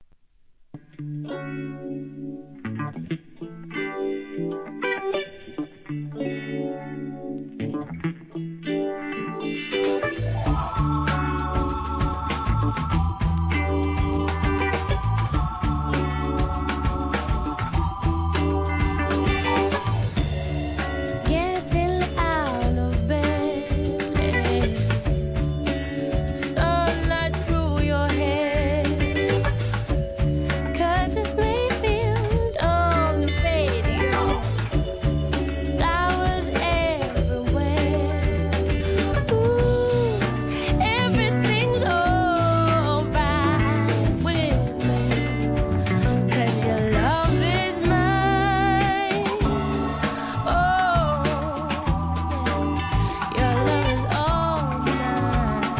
HIP HOP/R&B
ソウルフル&オーガニック